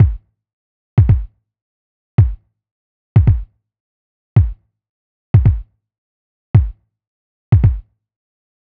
Unison Funk - 8 - 110bpm - Kick.wav